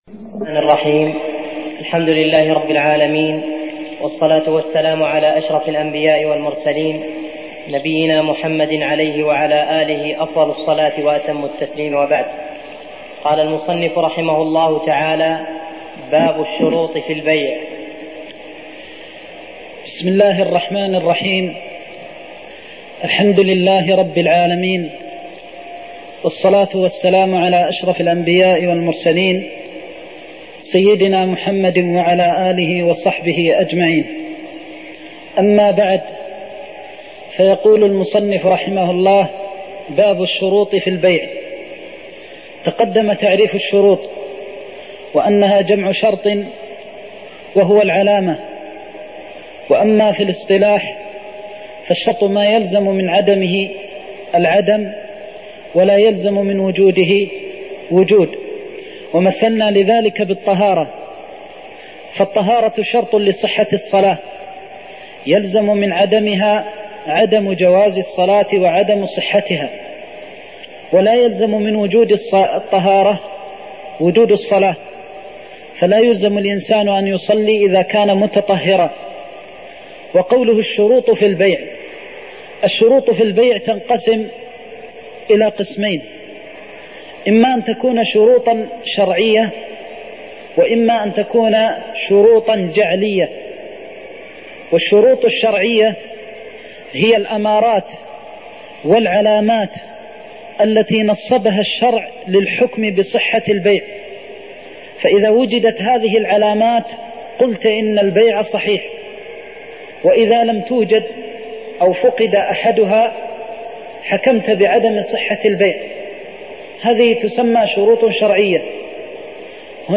تاريخ النشر ٢٧ صفر ١٤١٧ هـ المكان: المسجد النبوي الشيخ